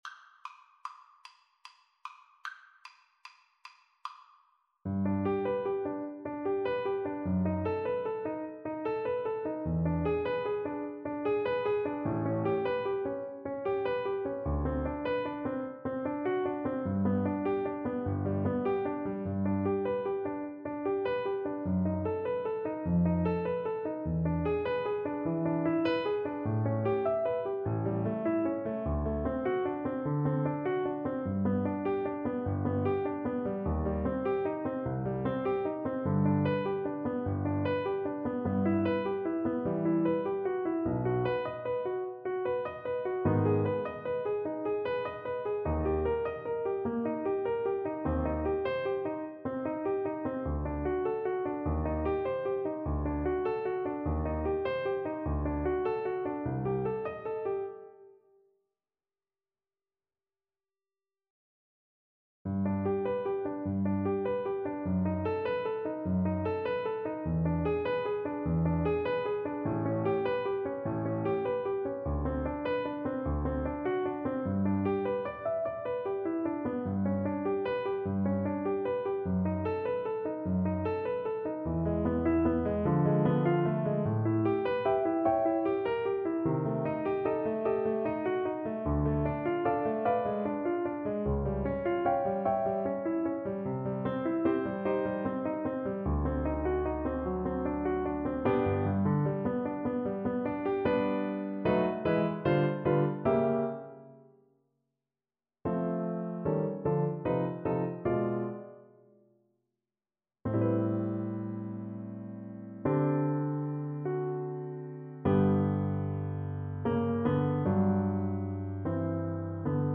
Classical Liszt, Franz Dreams of Love - Libestraume Notturno No. III Trombone version
G major (Sounding Pitch) (View more G major Music for Trombone )
= 50 Poco allegro con effetto
6/4 (View more 6/4 Music)
Classical (View more Classical Trombone Music)
Romantic music for trombone